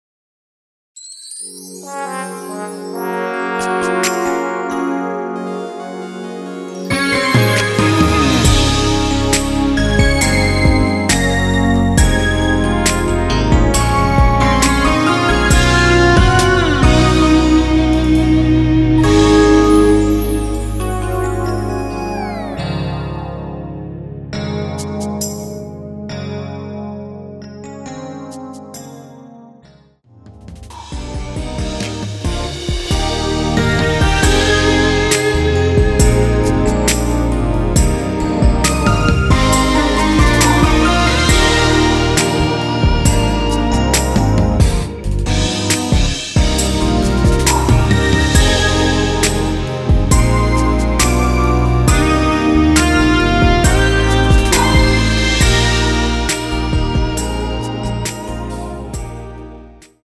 원키에서(-6)내린 MR입니다.
Db
앞부분30초, 뒷부분30초씩 편집해서 올려 드리고 있습니다.